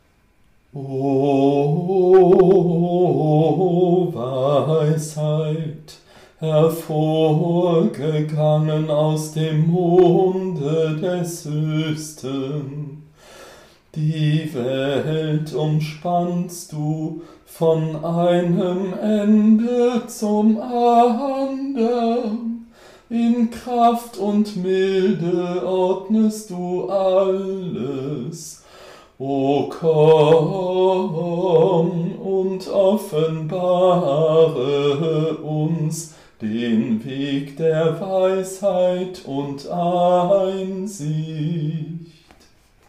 CANTICUM
Antiphon